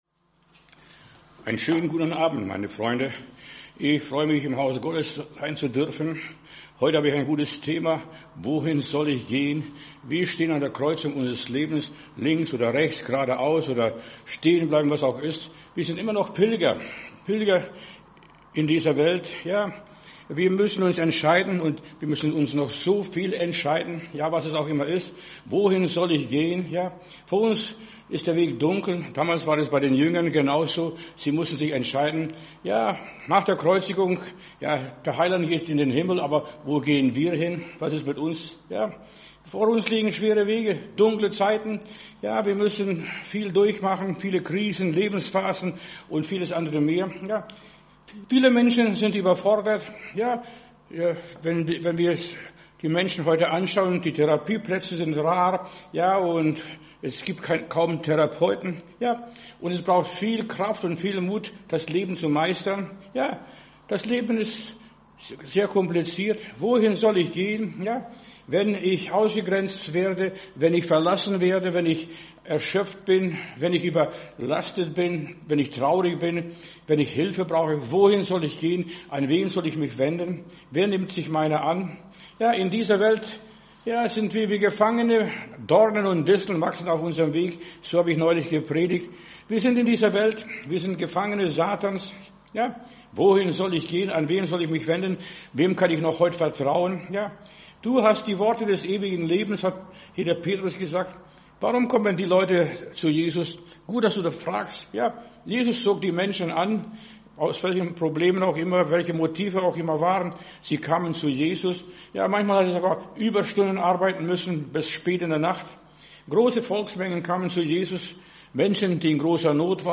Wer uns unterstützen möchte, kann dies hier tun: ♥ Spenden ♥ Predigt herunterladen: Audio 2026-02-27 Wohin sollen wir gehen?